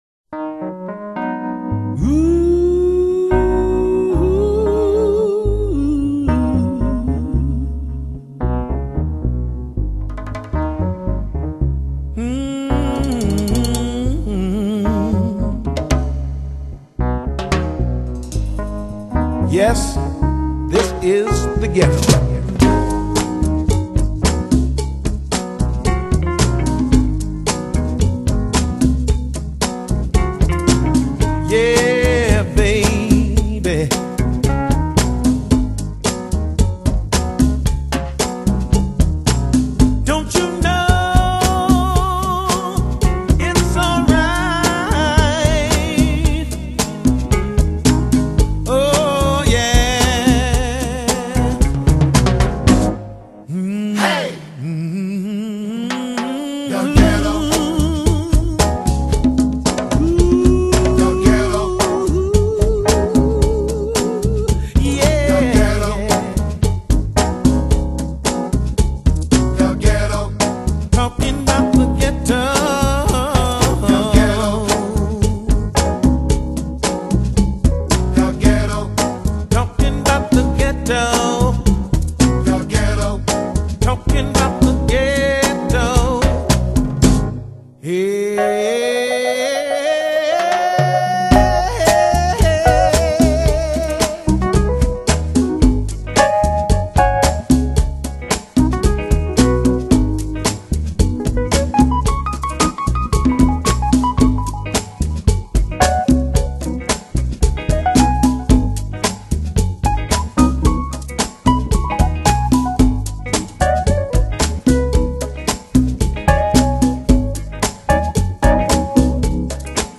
Genre: Lo-Fi